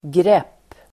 Uttal: [grep:]